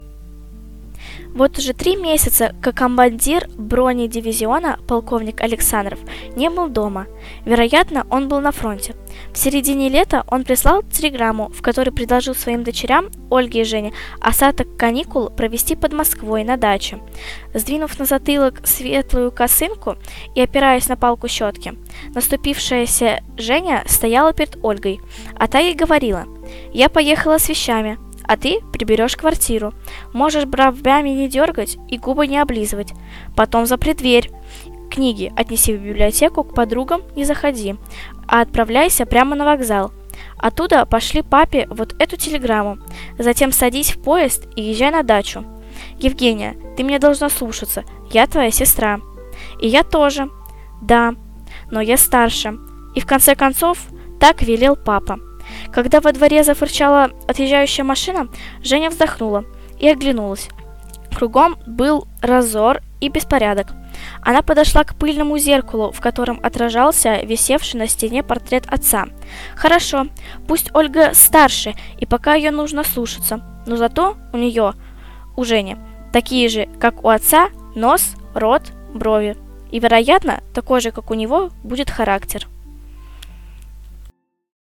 Январский подкаст студии звукозаписи Наумовской библиотеки приурочен к 120-летию со дня рождения детского писателя Аркадия Петровича Гайдара.
А потом вместе записали аудиотреки отрывков из произведений «Чук и Гек», «Голубая чашка», «Горячий камень» и, конечно, «Тимур и его команда».
Rasskaz-Timur-i-ego-komanda-Arkadij-Gajdar.mp3